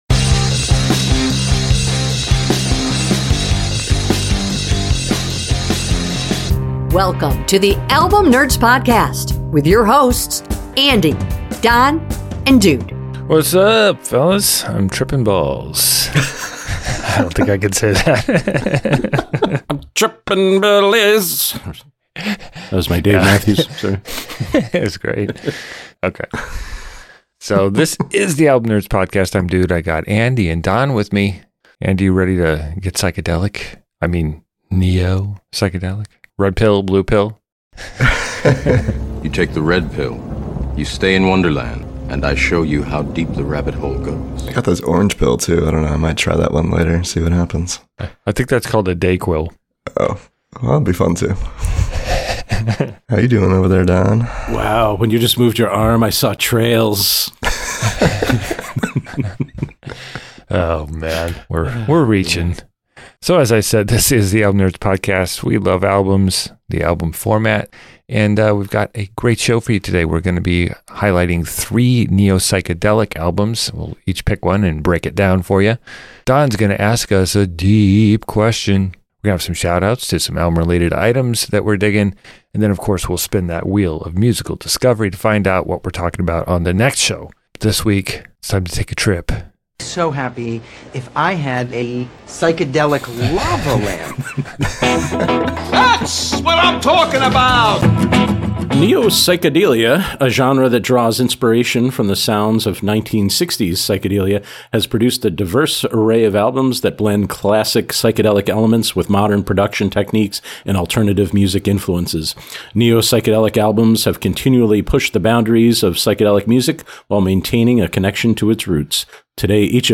Album picks on a range of topics selected by the all knowing Wheel of Musical Destiny. Three friends and music nerds discuss classic albums across a variety of genres including rock, metal, country, hip-hop, rnb and pop.